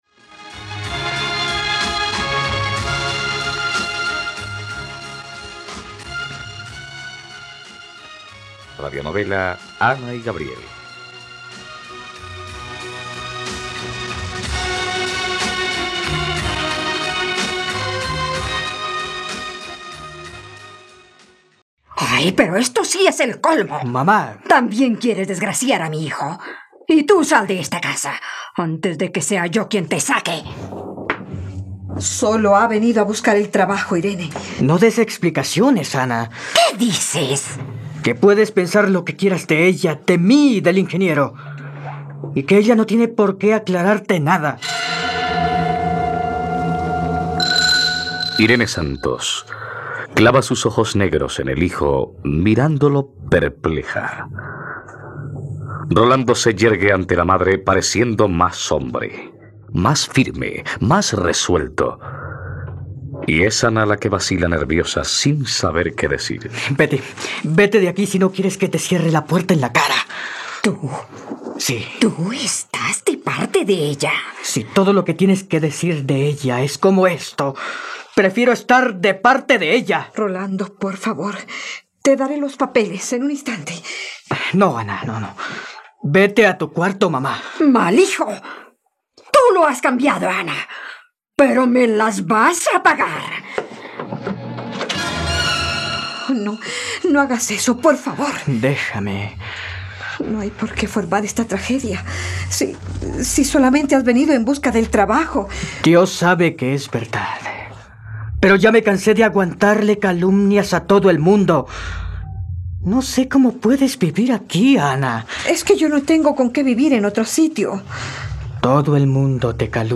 ..Radionovela. Escucha ahora el capítulo 48 de la historia de amor de Ana y Gabriel en la plataforma de streaming de los colombianos: RTVCPlay.